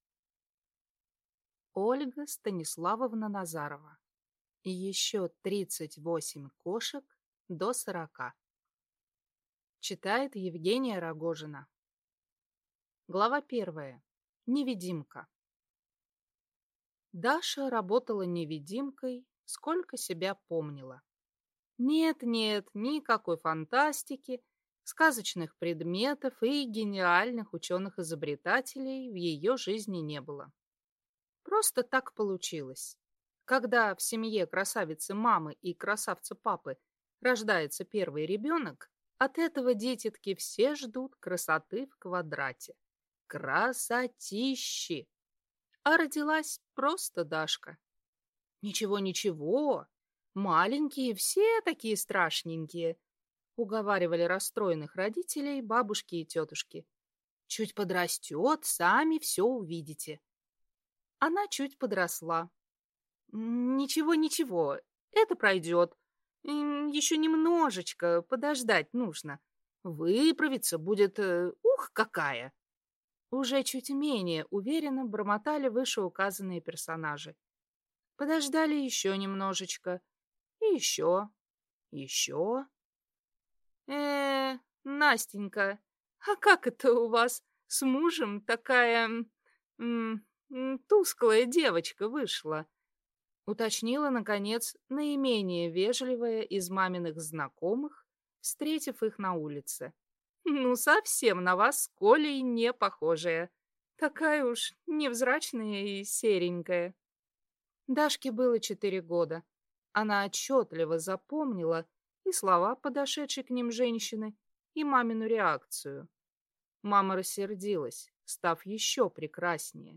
Аудиокнига Ещё тридцать восемь кошек до сорока | Библиотека аудиокниг